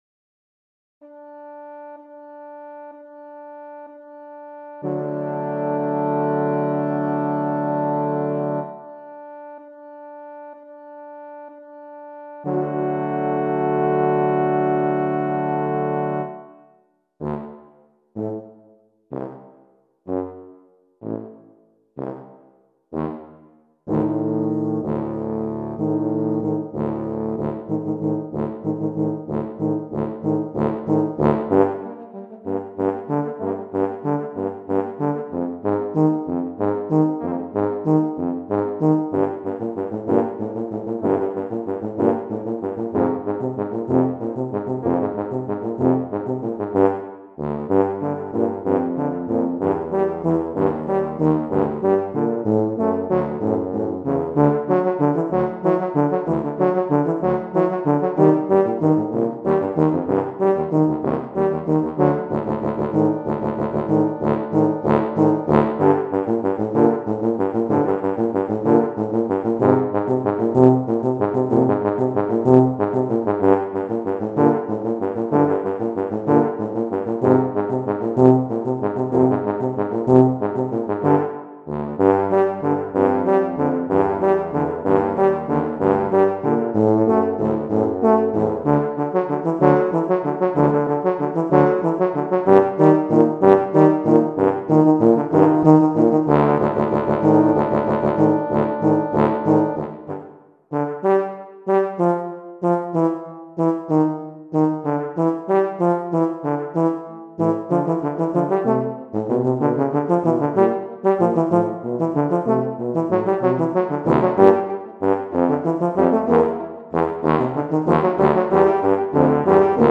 Voicing: Tuba Ensemble